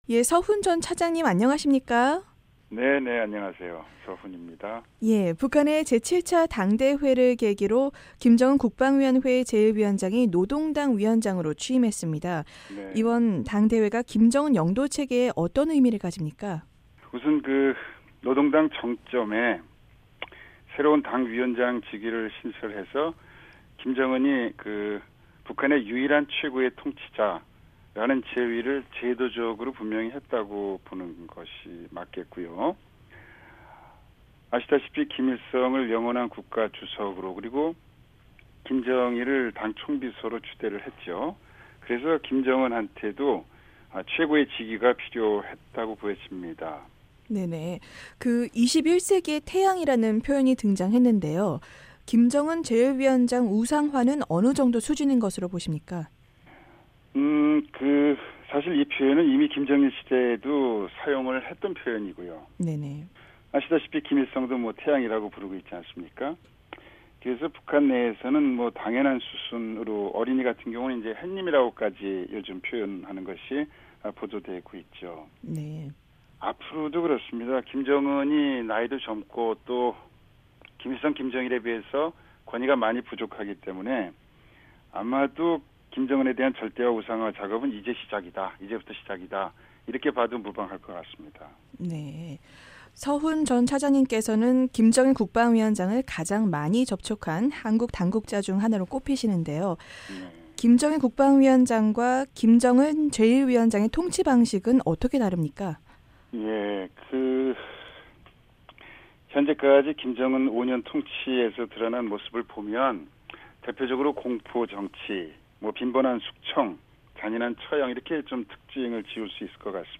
북한에서는 김정은 위원장의 공포정치가 계속될 것이며 장성택 같은 2인자는 상당 기간 나오기 힘들다고, 서훈 전 한국 국정원 북한담당 차장이 VOA와의 인터뷰에서 내다봤습니다.